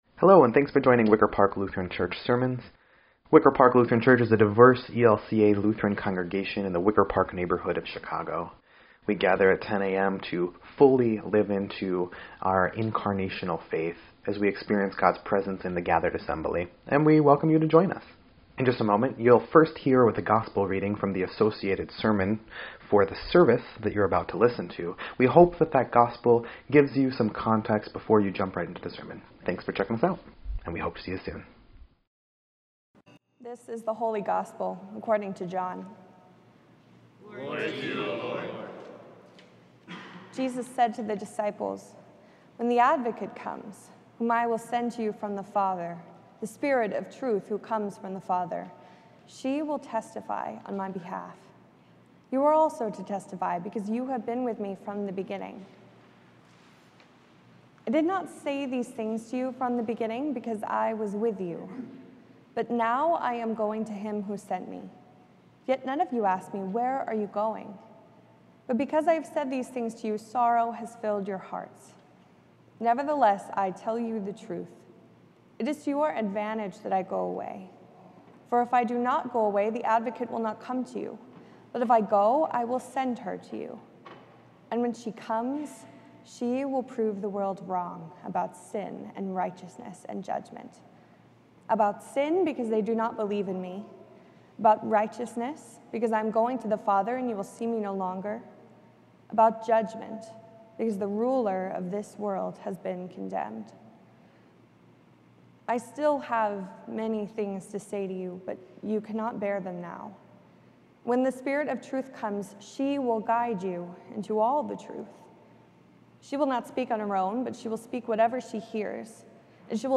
5.19.24-Sermon_EDIT.mp3